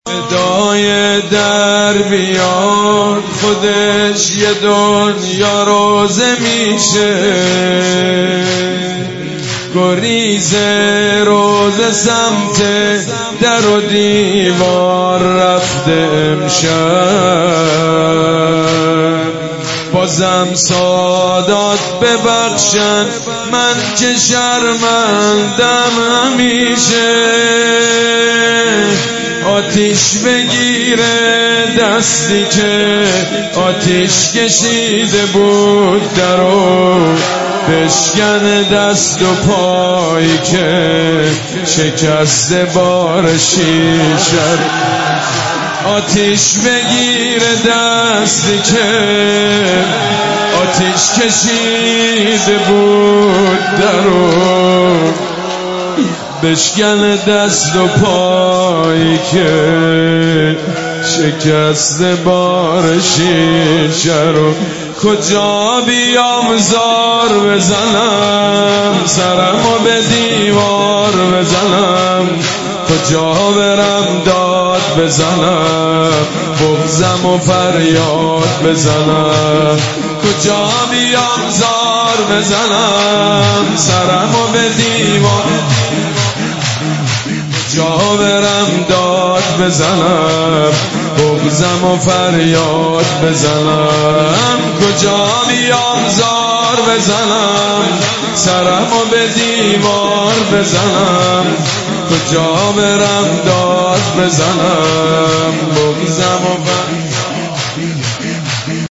دانلود مداحی شهادت حضرت زهرا (س)شب سوم فاطمیه سید مجید بنی فاطمه
روضه ایام فاطمیه